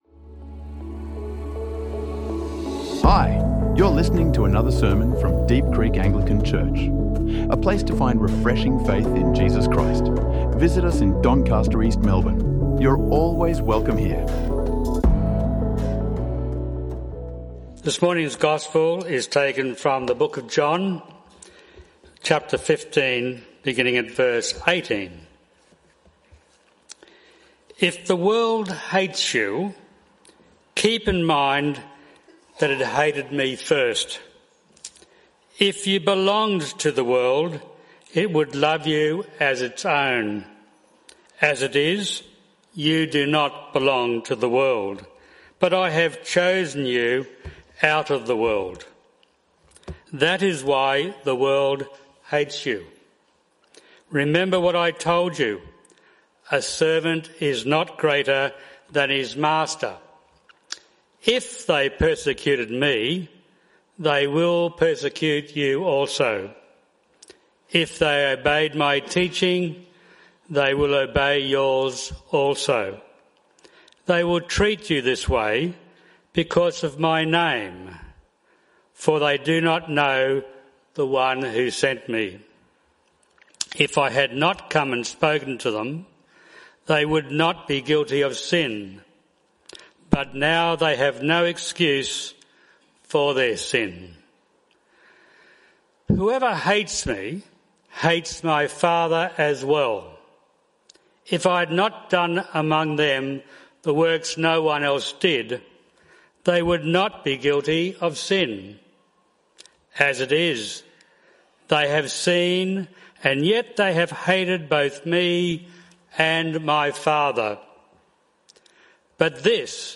Jesus Expects Us to be Persecuted and Makes Us His Witness | Sermons | Deep Creek Anglican Church